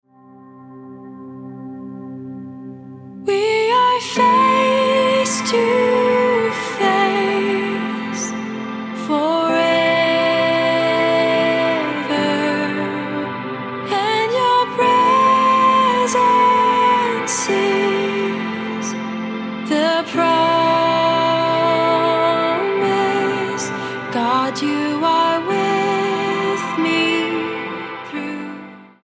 STYLE: Pop
heartfelt vocal performance